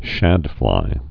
(shădflī)